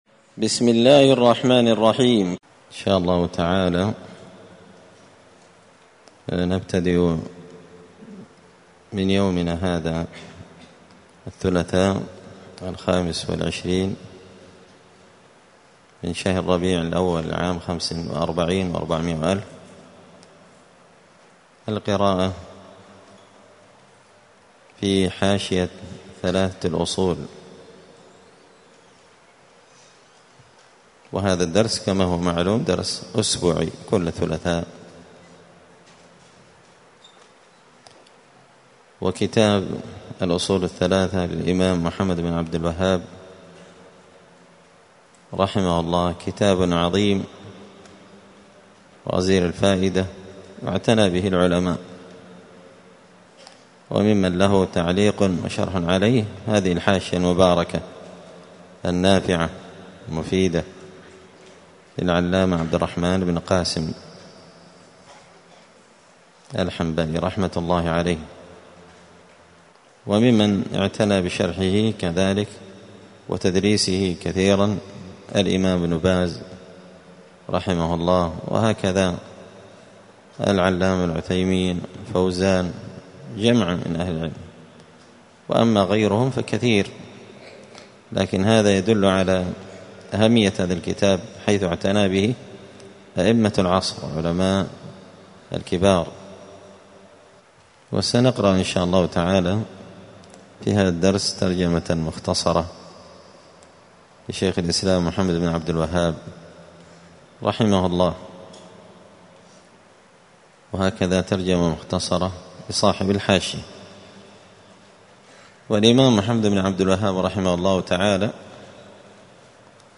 مسجد الفرقان قشن_المهرة_اليمن 📌الدروس الأسبوعية